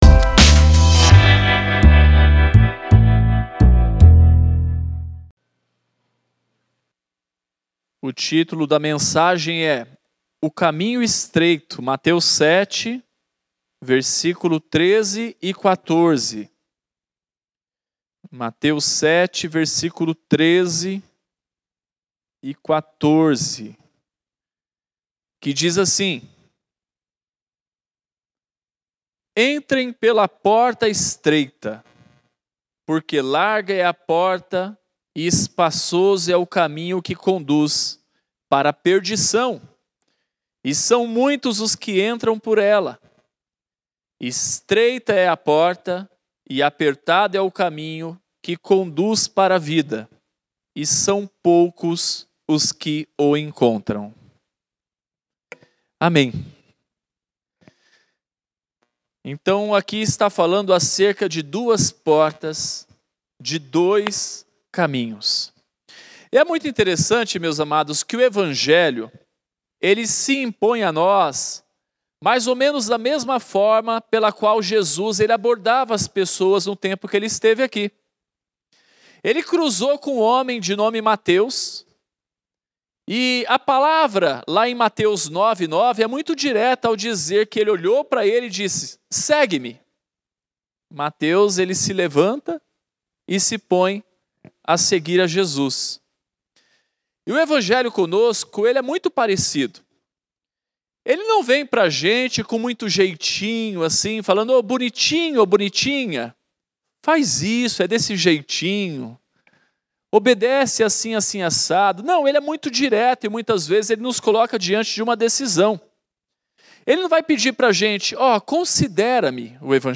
Mensagem realizada no Culto de Reflexão de Oração.